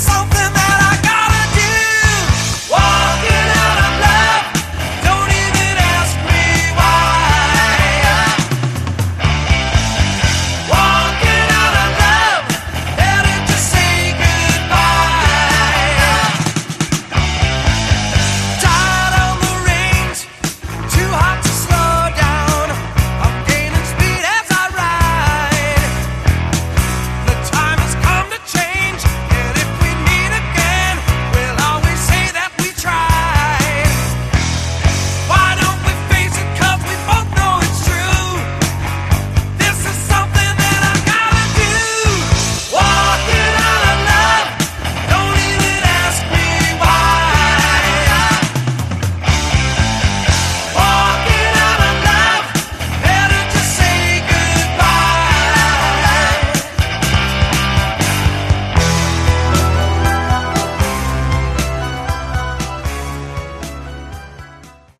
Category: AOR
vocals, bass
keyboards, guitar
drums